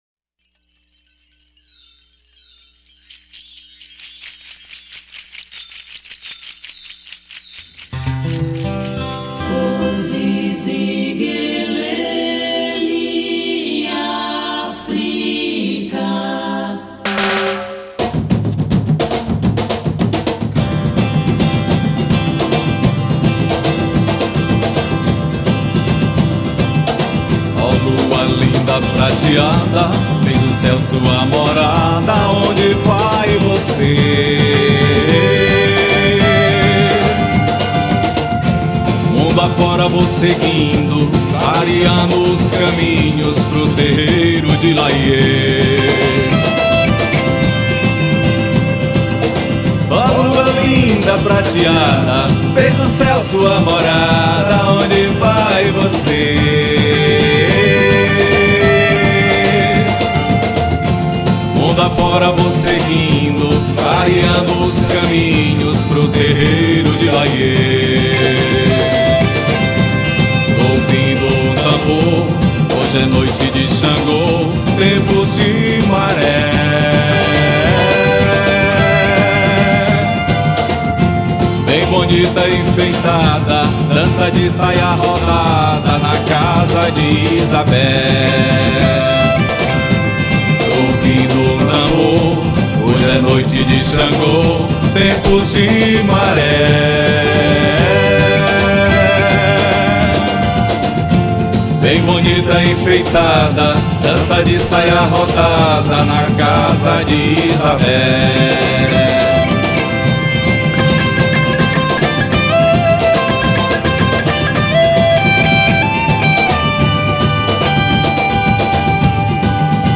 647   05:10:00   Faixa:     Rock Nacional